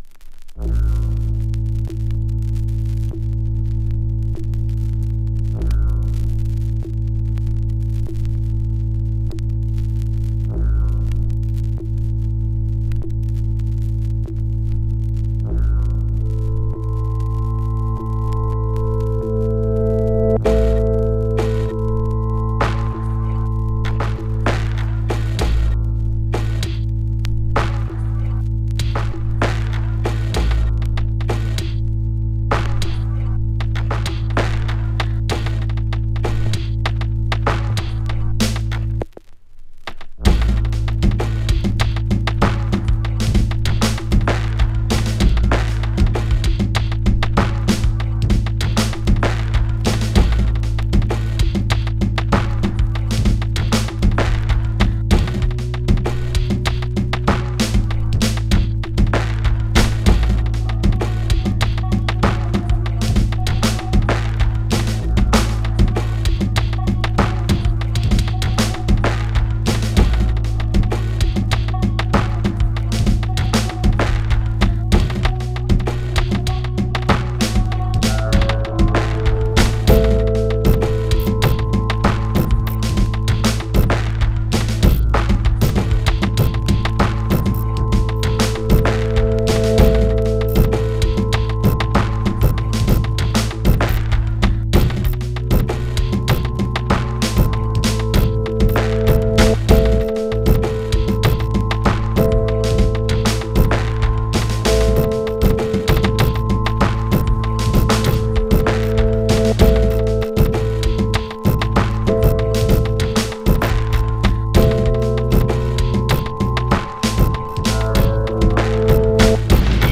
> JAZZY BREAK/ELECTRONICA/ABSTRACT